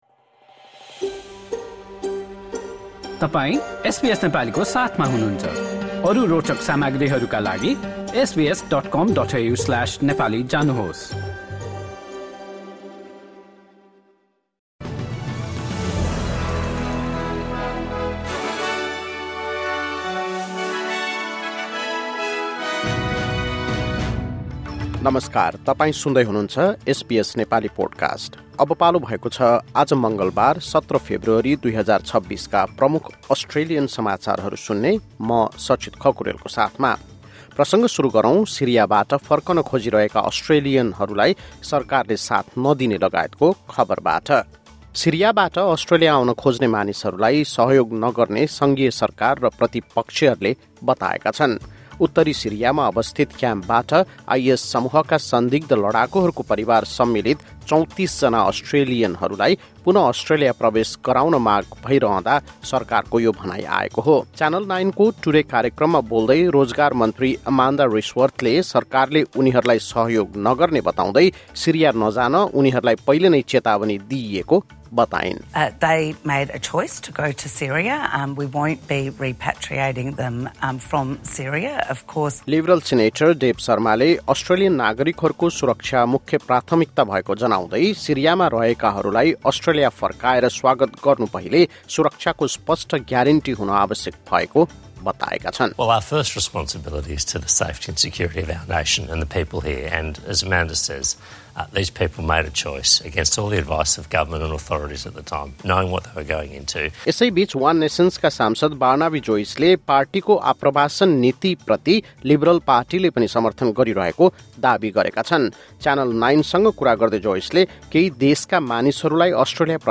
SBS Nepali Australian News Headlines: Tuesday, 17 February 2026